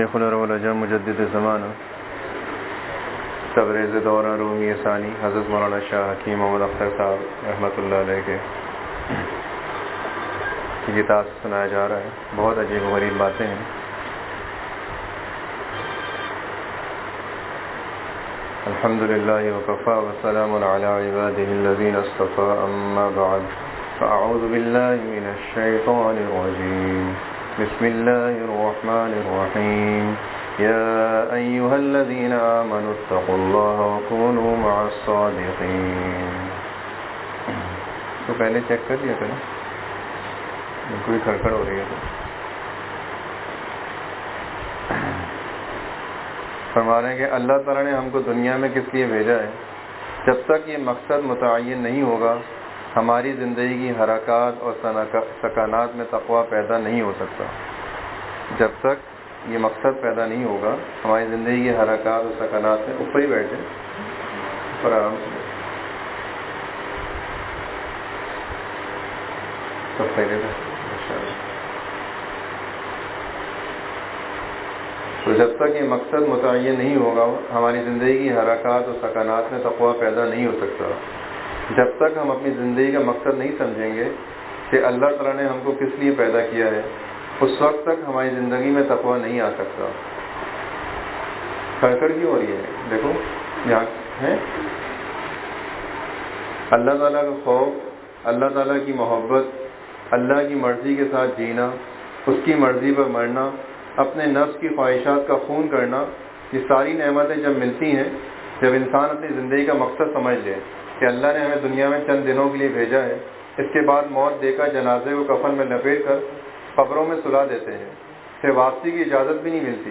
5.4.26 Itwar Majlis ( Allah Talah ki Marzi per Jeena Seekhain, Allah Talah ki Marzi Per Marna Seekhain, Dard e Dil Majlis ,Hazratwala rah ki ajeeb baatain)